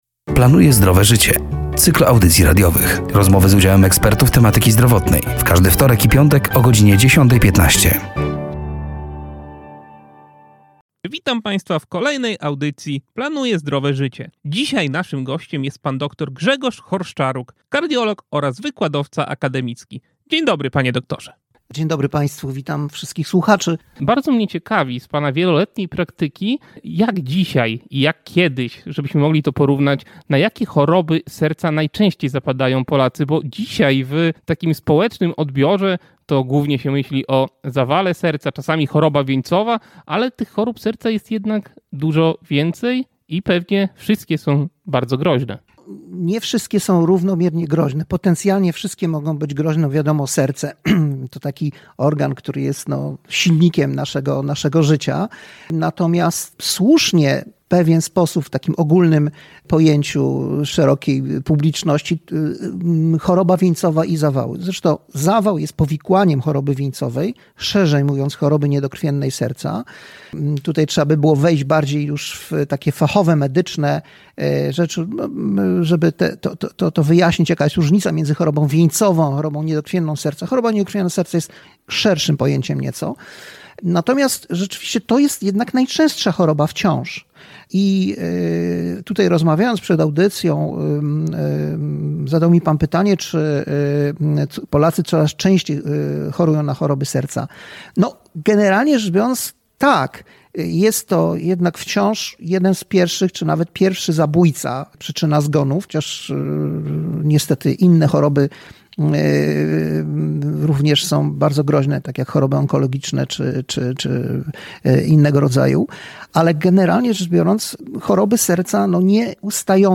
,,Planuję Zdrowe Życie”, to cykl audycji radiowych poświęconych upowszechnianiu wiedzy z zakresu zdrowego stylu życia, promujących zdrowie i edukację zdrowotną. Rozmowy z udziałem ekspertów tematyki zdrowotnej.